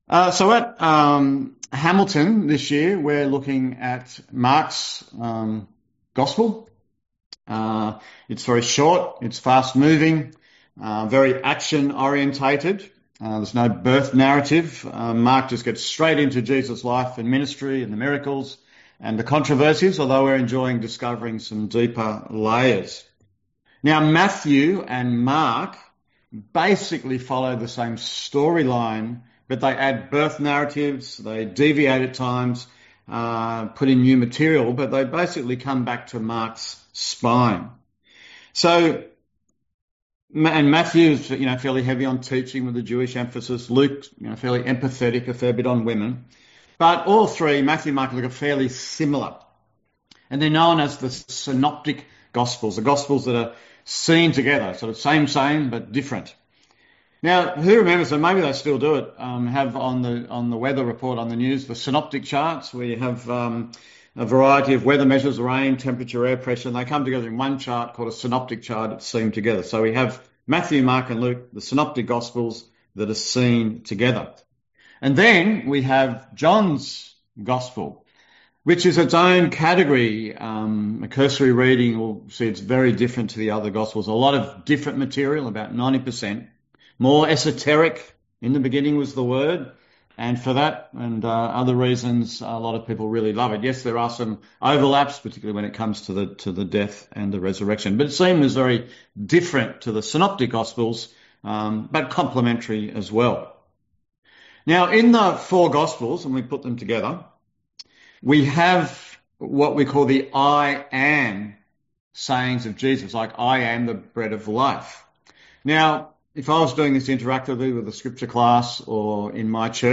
A sermon on John 11:1-45 and Ezekiel 37:1-14